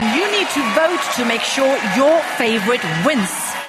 My attention, wandering during the contest’s voting stage, was suddenly recaptured by a reference to your favourite wince: